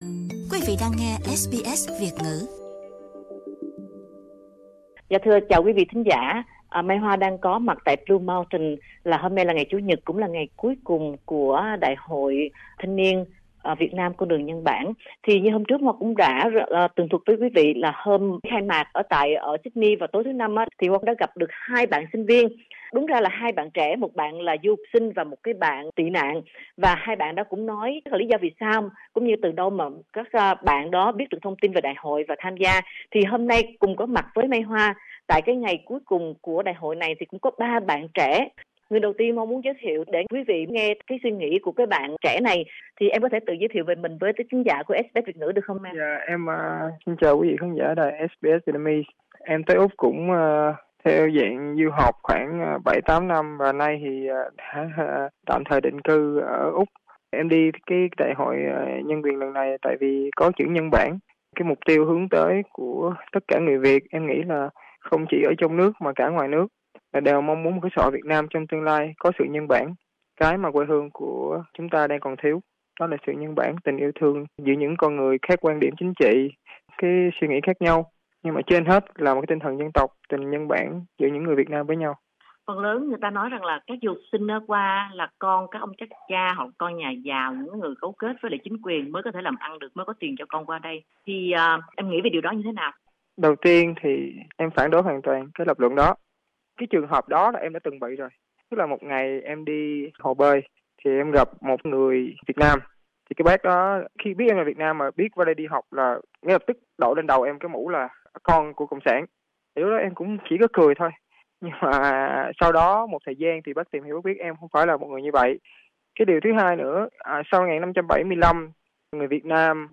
Phỏng vấn đặc biệt 3 du học sinh tham dự Đại hội Giới trẻ Thế giới - Việt Nam Con đường Nhân bản